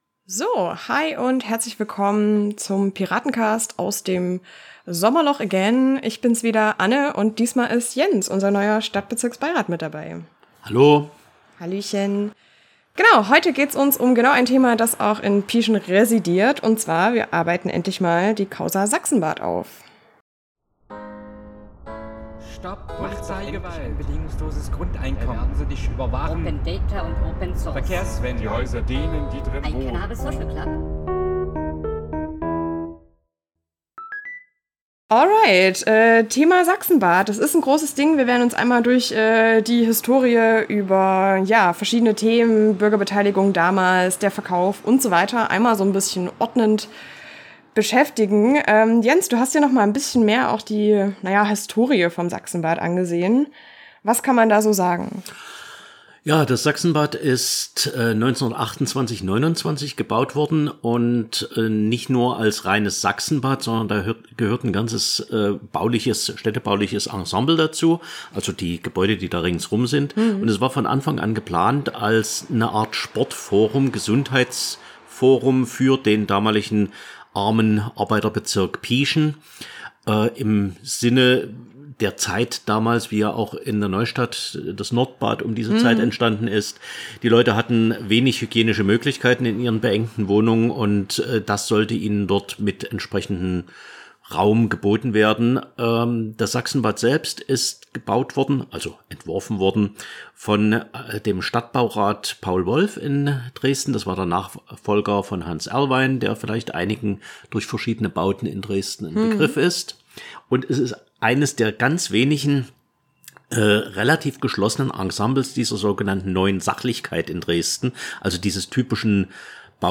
Jens (SBR für Pieschen) und Anne (als Stadträtin in Pieschen gewählt) sprechen deshalb über die gesamte Causa Sachsenbad: Hintergründe, Bürgerforum und Verkauf, Mauscheleien in der Stadtverwaltung und alle aktuellen Entwicklungen.